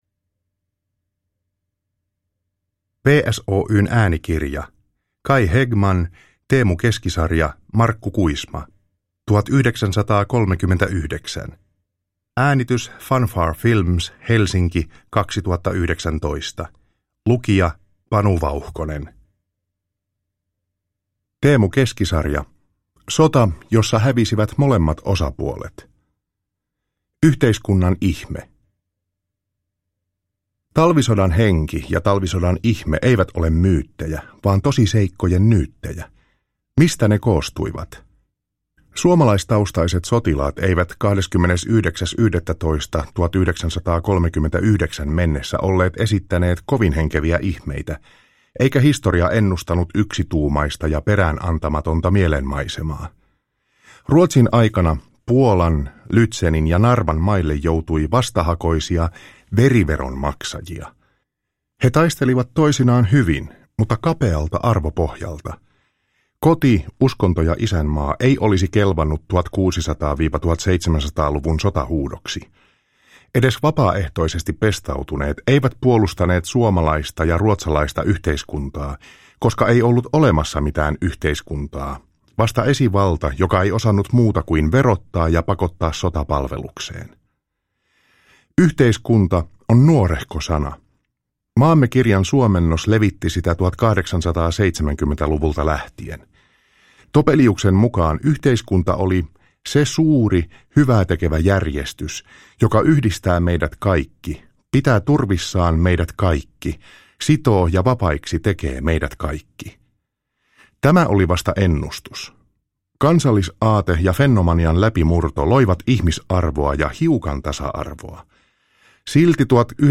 1939 – Ljudbok – Laddas ner